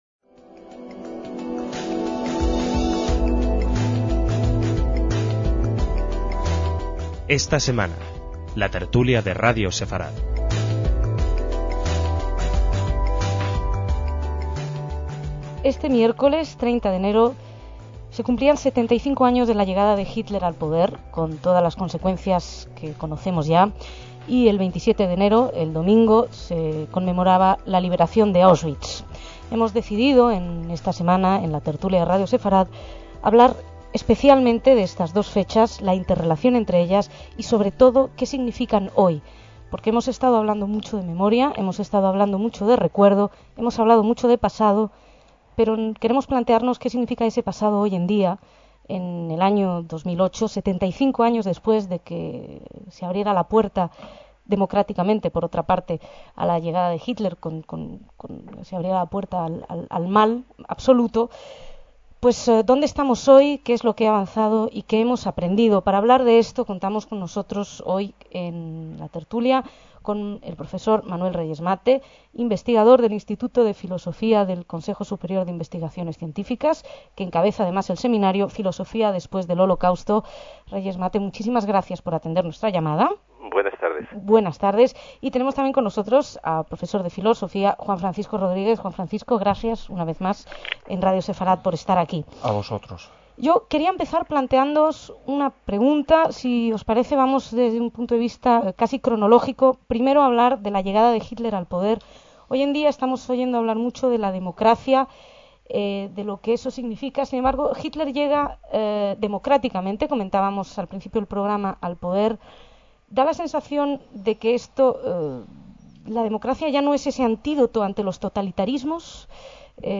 DECÍAMOS AYER (2/2/2008) - En 2008 se cumplían 75 años de la llegada de Hitler al poder en Alemania. Para hablar de ello participaron en la tertulia